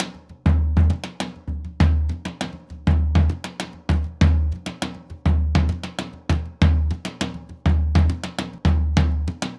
Surdo Candombe 100_2.wav